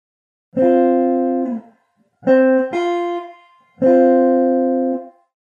Nella fattispecie l'intervallo fra C ed F è un intervallo di quarta, poiché per definizione l'intervallo di quarta è una distanza di cinque semitoni e fra C ed F ci sono proprio cinque semitoni di distanza [